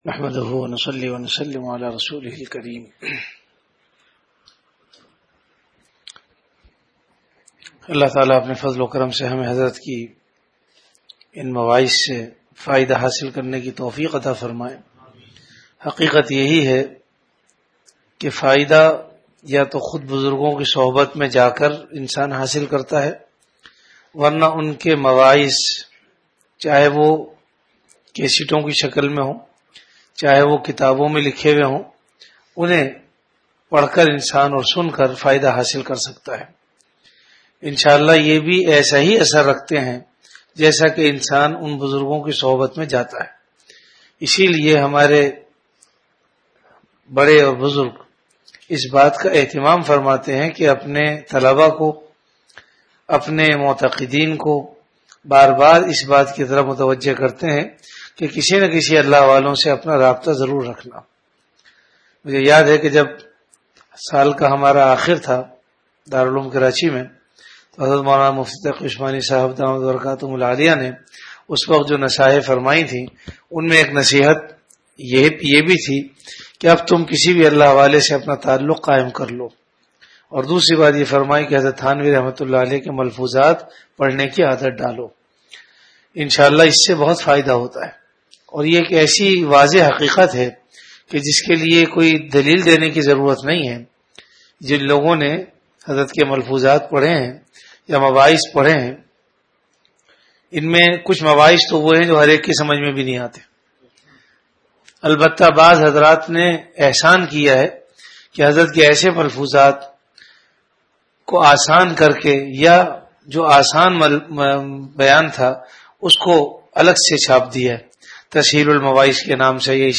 Delivered at Home.
Majlis-e-Zikr · Home Majlis e Zikr(Sun-17Jan2010